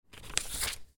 Download Turn Page sound effect for free.